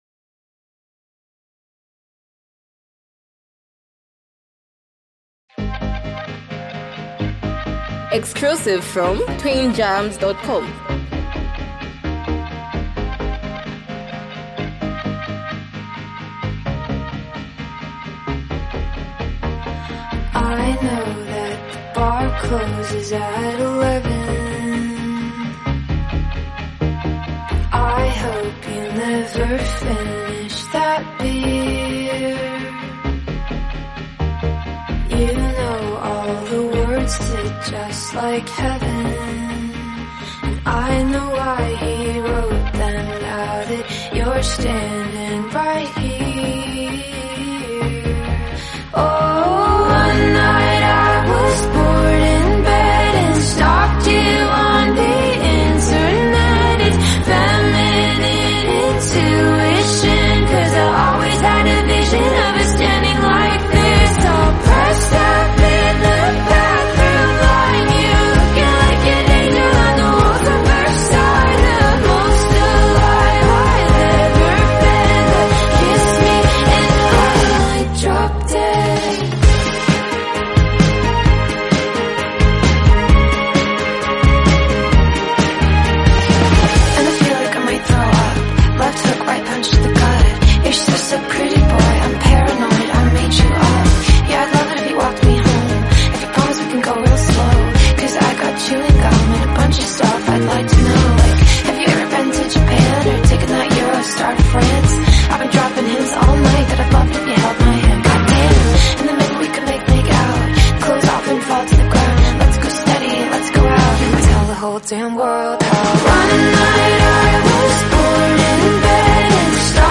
A fierce, emotionally charged anthem